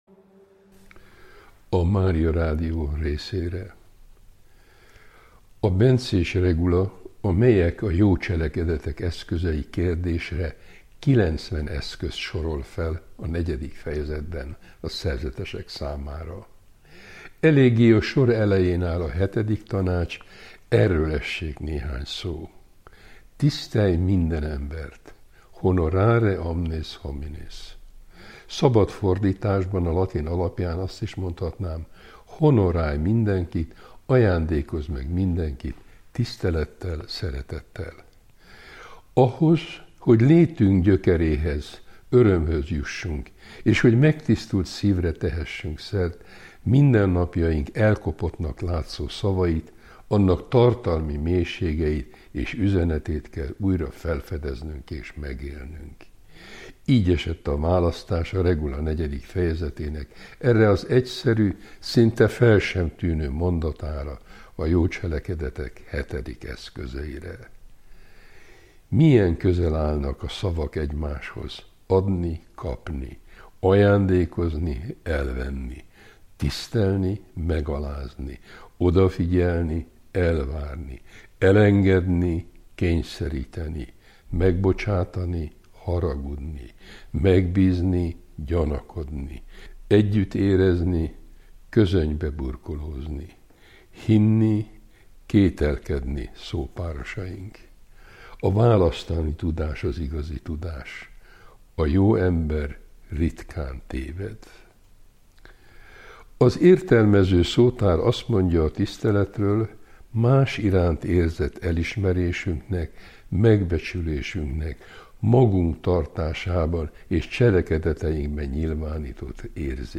Szent Benedek öröksége Várszegi Asztrik emeritus pannonhalmi főapát tolmácsolásában